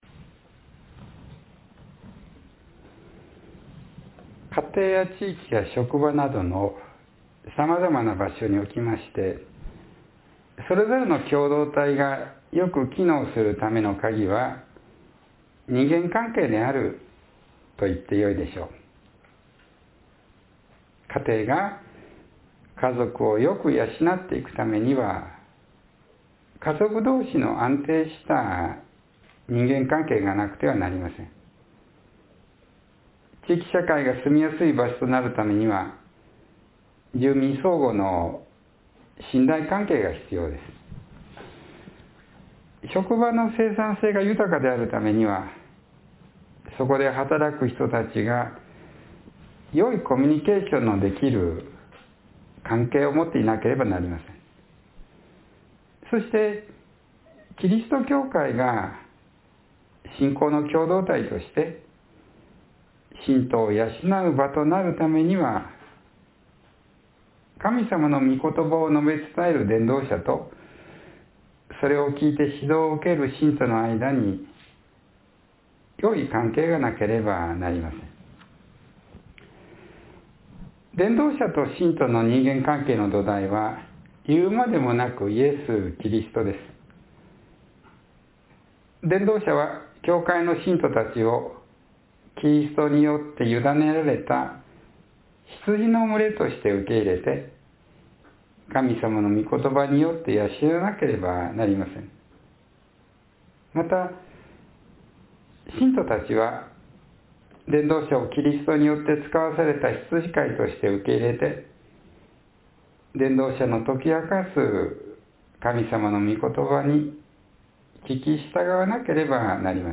（11月13日の説教より）